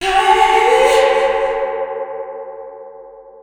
Techno / Voice